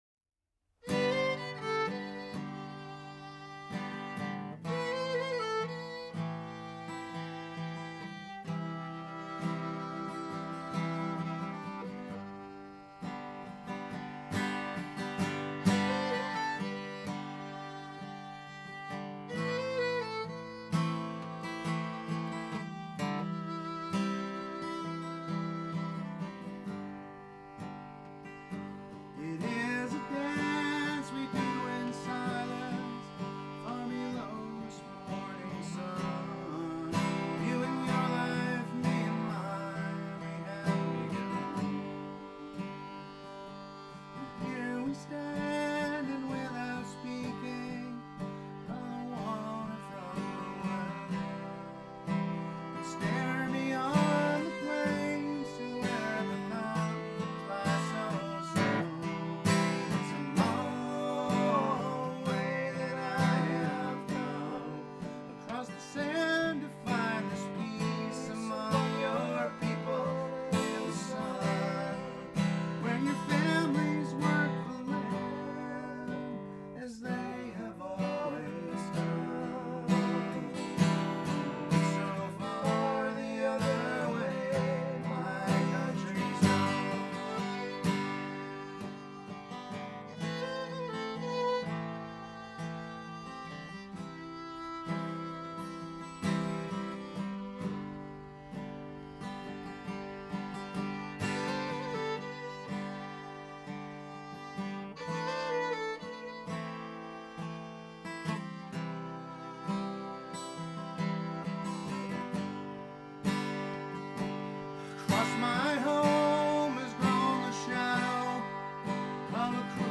cover